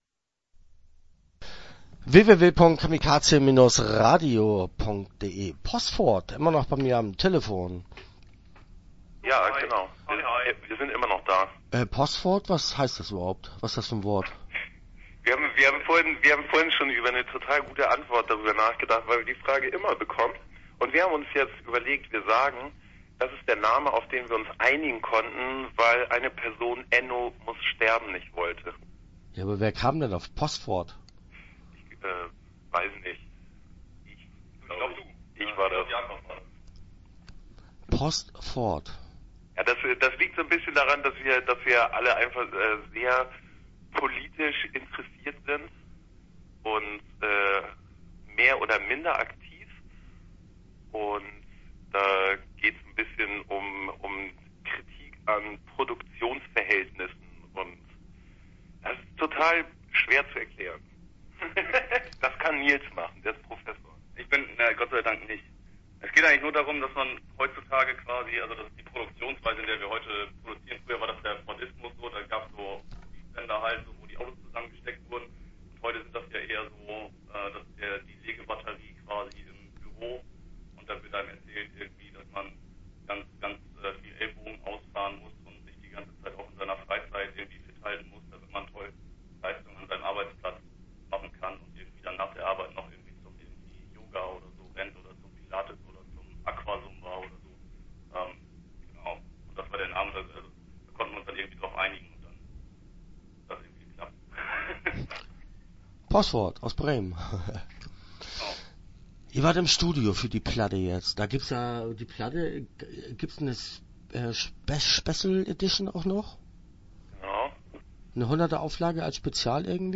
Postford - Interview Teil 1 (10:02)